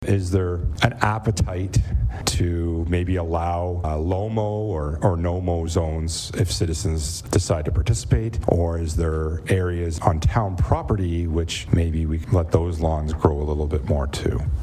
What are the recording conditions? Renfrew Collegiate Institute sent a delegation to meet with town council on Tuesday night to discuss the concept of “No Mow May,” a movement where people are intentionally avoiding mowing their lawns for the benefit of the area’s wildlife and ecosystem.